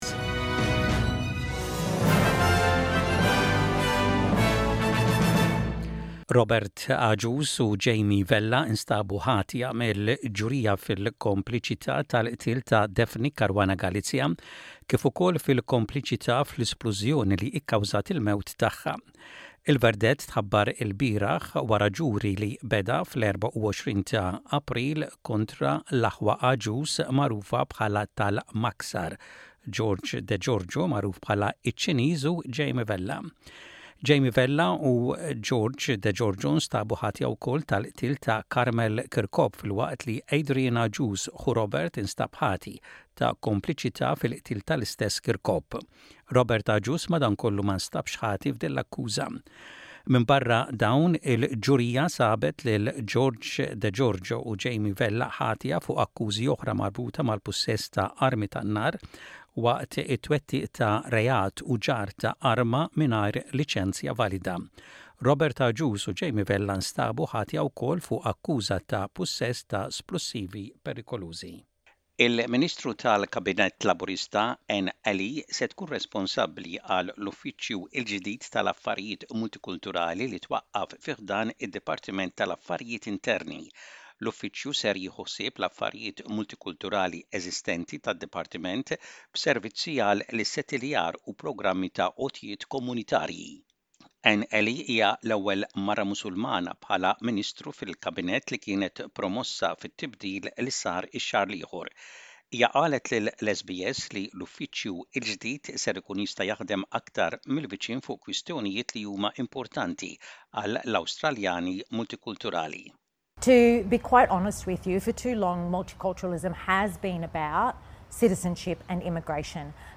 Aħbarijiet bil-Malti: 06.06.25